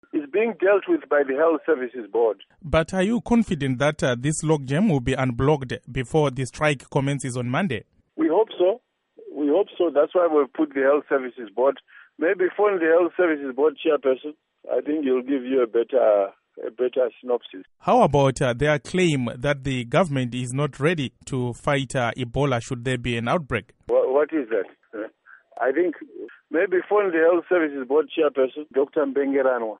Interview With Dr David Parirenyatwa